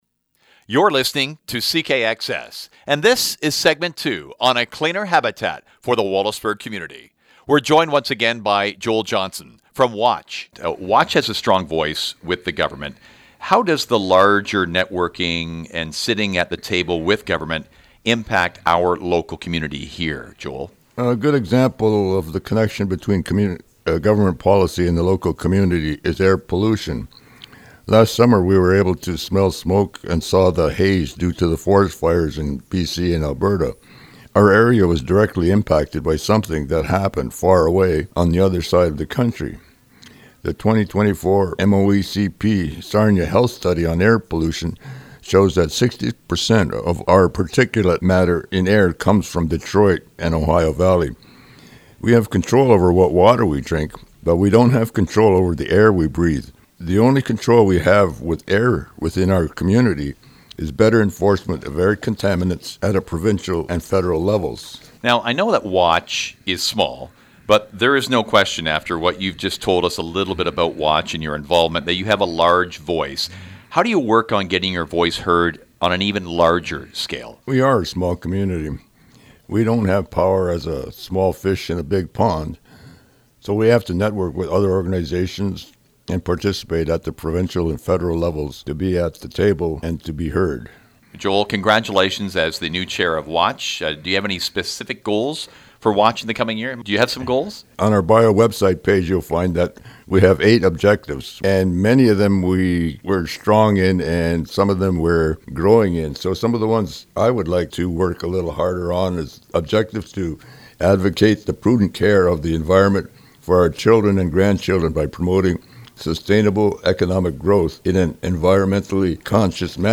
Radio Interview Segment 1 Radio Interview Segment 2
Watch Segment 2 Radio.mp3